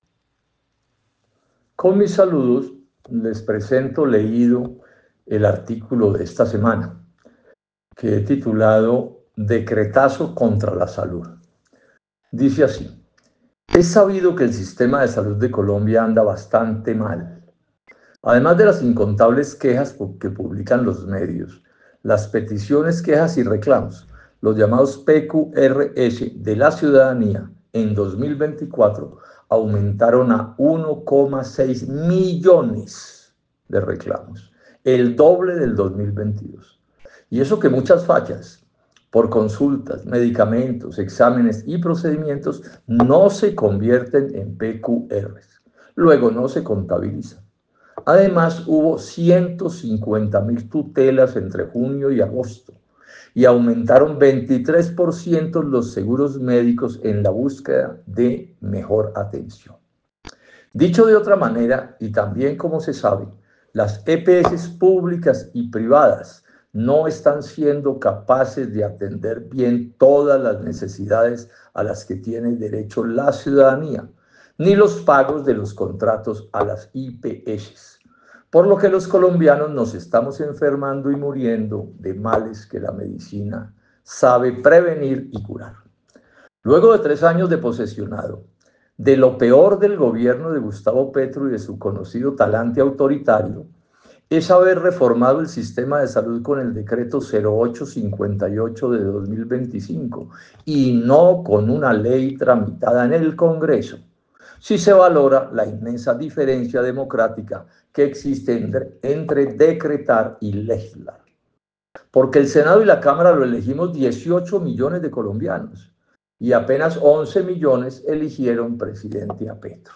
Lectura: